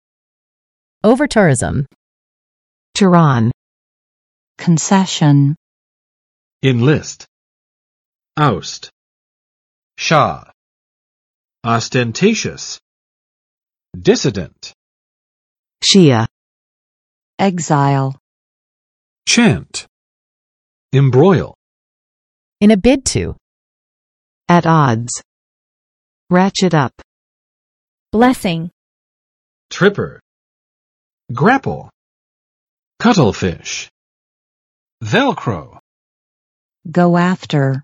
[ˌovɚˈtʊrɪzəm] n. 热门景点或观光胜地因游客爆量而不能永续经营的现象